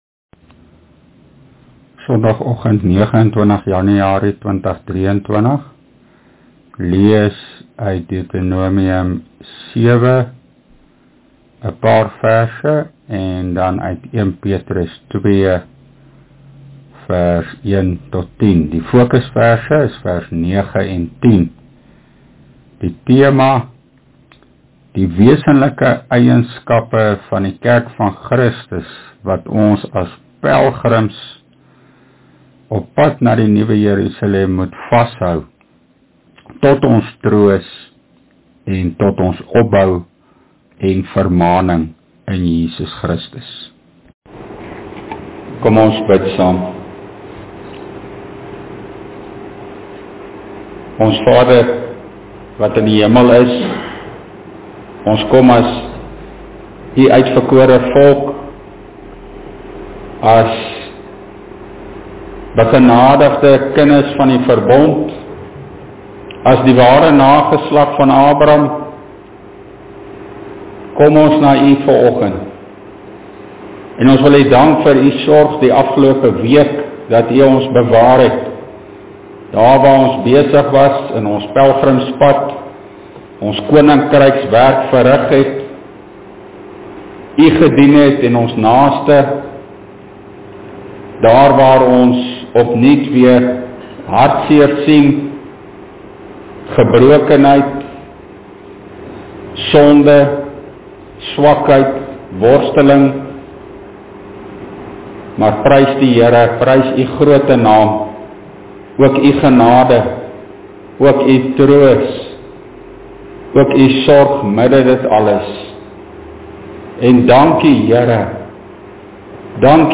Opname (GK Carletonville, 2023-01-29)